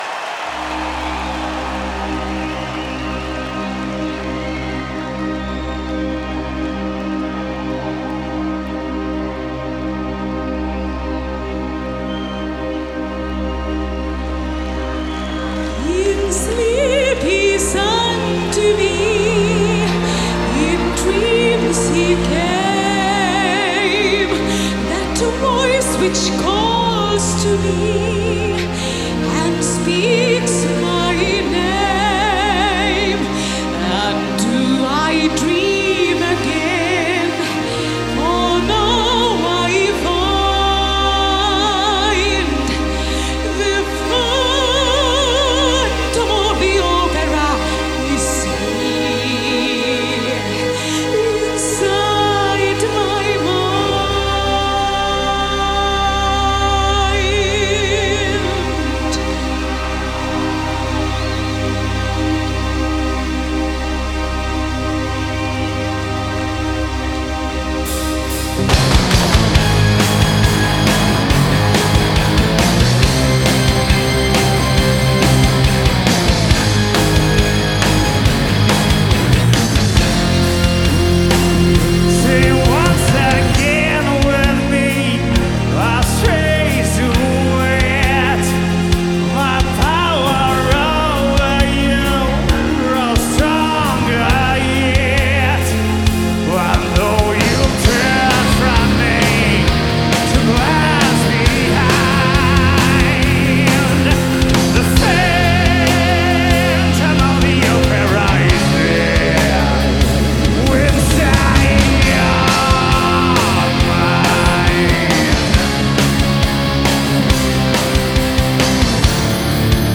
Genre: Symphonic Rock